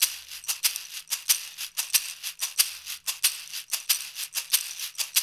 93 -UDU S0D.wav